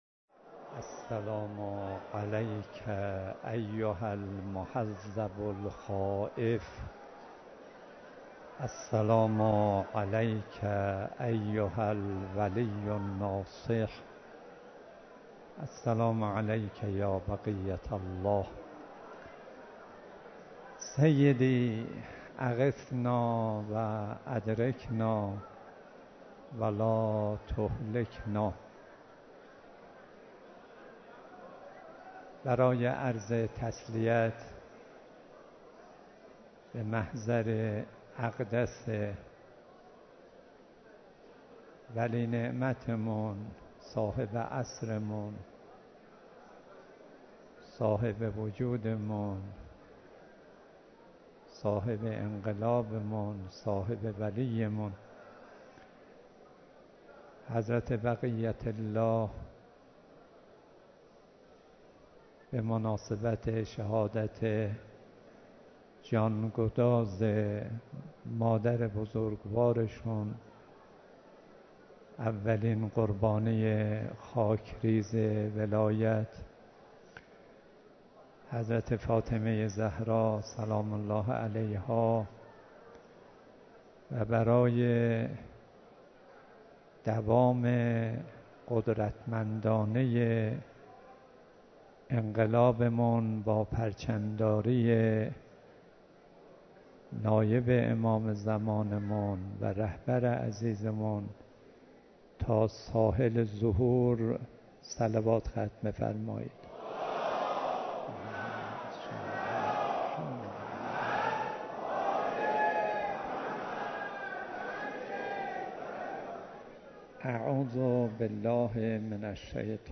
سخنرانی حجت الاسلام و المسلمین جناب آقای صدیقی